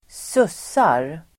Ladda ner uttalet
sussa verb (vardagligt), sleep [informal]Grammatikkommentar: A &Uttal: [²s'us:ar] Böjningar: sussade, sussat, sussa, sussarSynonymer: sovaDefinition: sova